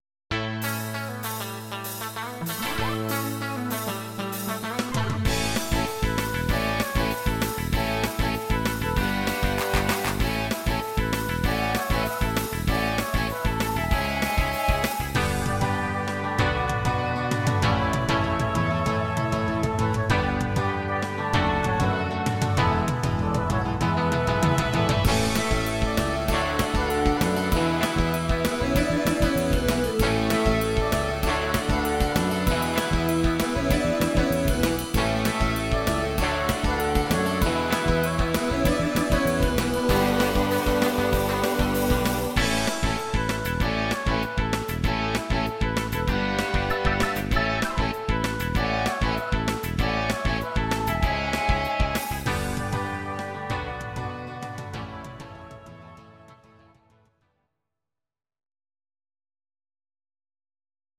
Audio Recordings based on Midi-files
Pop, Musical/Film/TV, 1990s